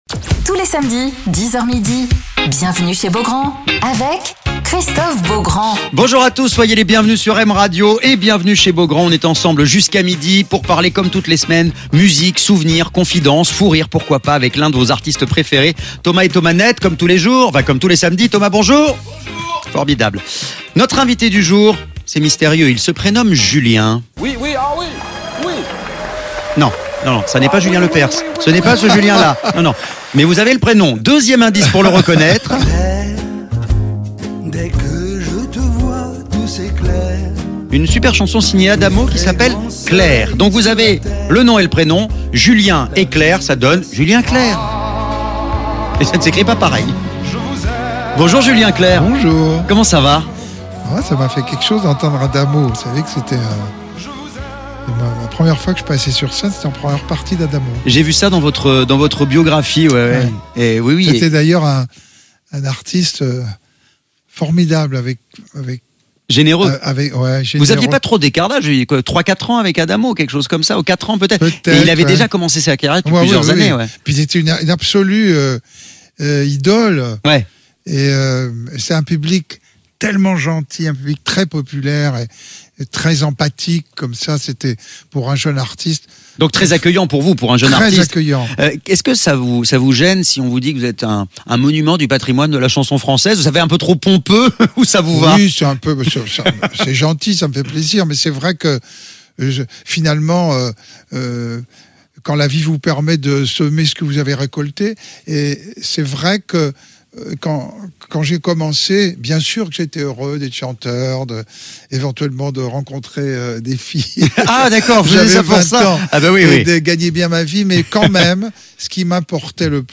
Alors qu'il a sorti son album "Une vie", Julien Clerc est l'invité de Christophe Beaugrand sur M Radio